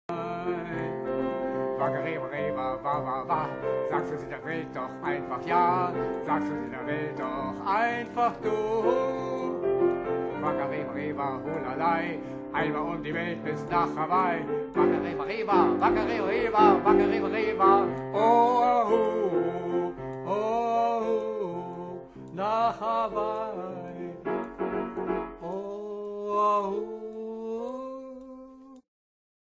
Musical-Komödie für fünf Schauspieler
Klavier und Gesang